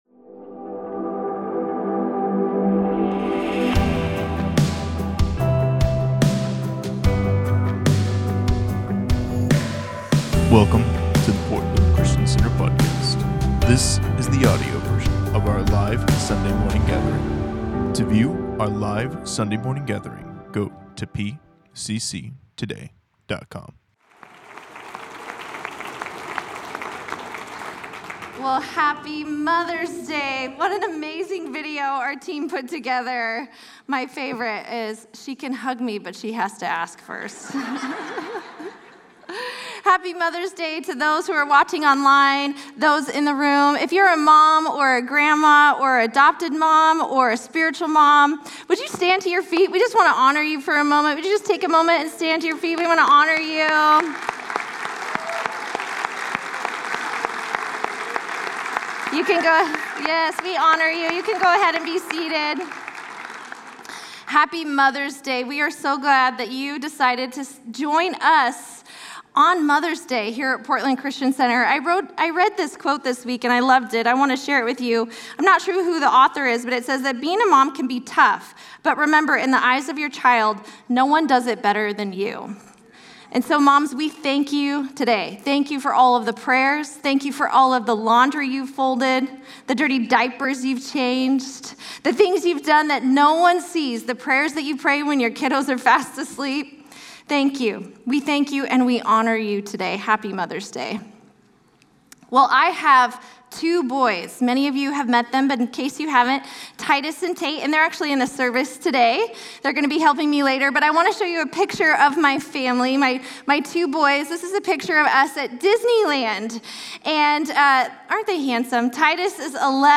Sunday Messages from Portland Christian Center Mother's Day May 14 2023 | 00:34:08 1x 00:00 / 00:34:08 Subscribe Share Spotify RSS Feed Share Link Embed